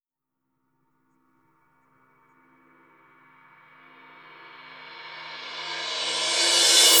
Track 02 - Backward Cymbal OS 01.wav